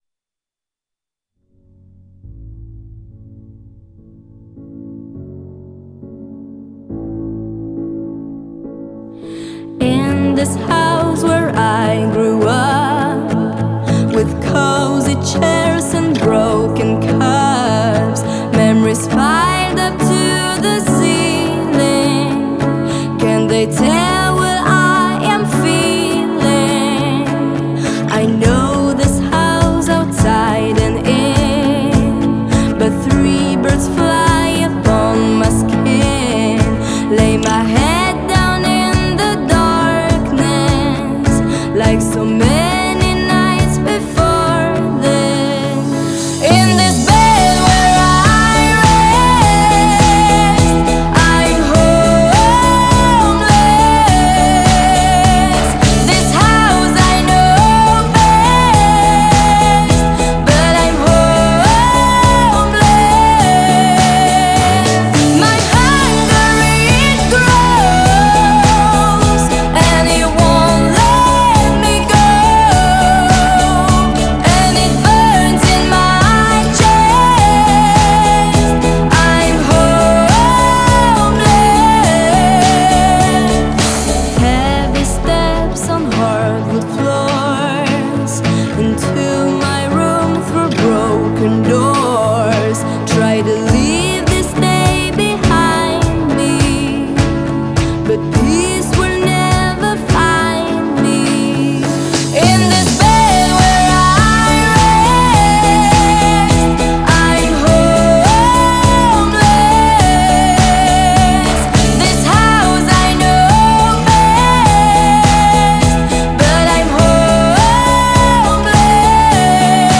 Genre: Pop, Female vocalists